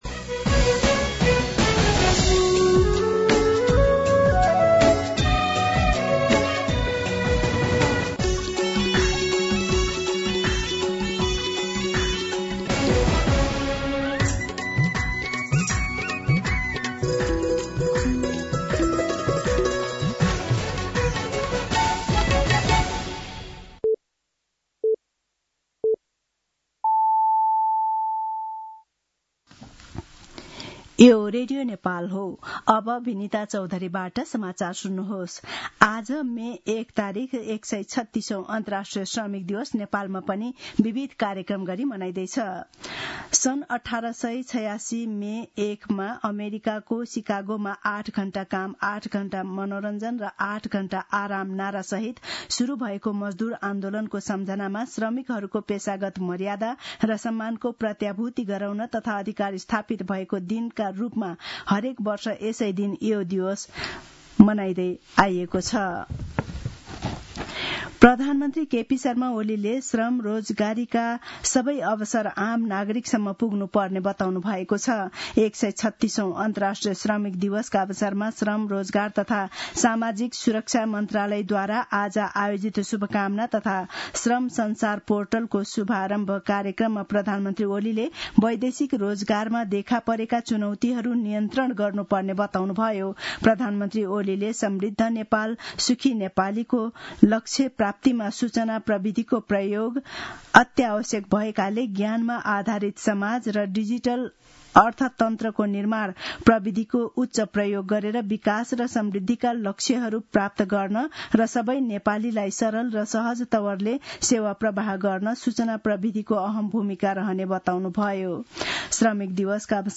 दिउँसो १ बजेको नेपाली समाचार : १८ वैशाख , २०८२